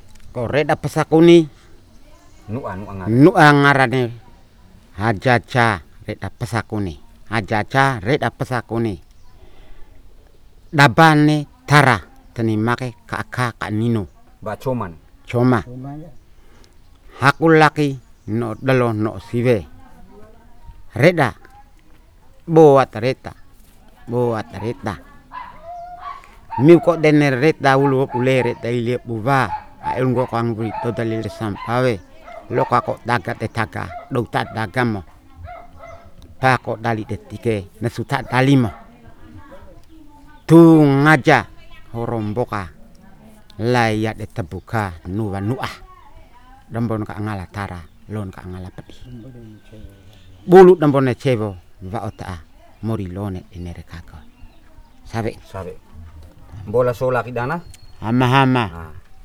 Genre: Healing/ritual language.
Palu'e, Flores, Nusa Tenggara Timur, Indonesia. Recording made in Tu'a plantation, man from kampong Lei, Kéli domain.